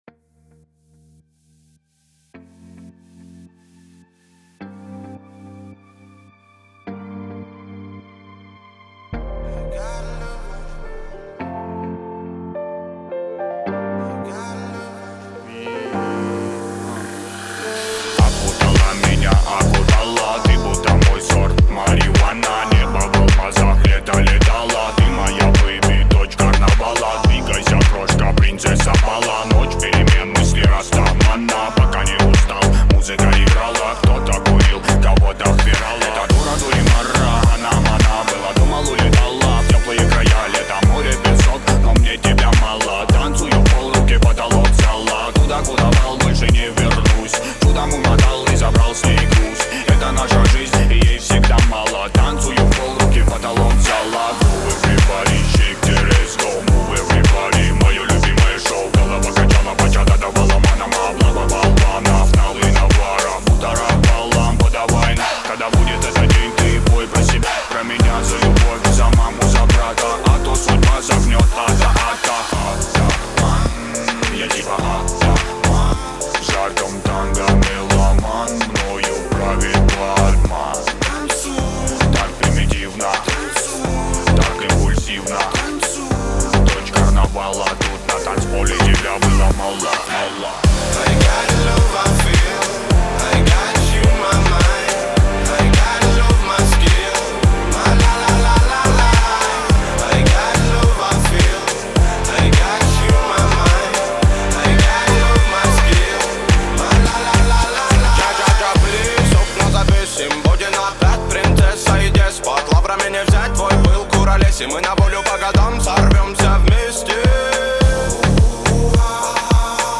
Трек размещён в разделе Клубная музыка | Ремиксы.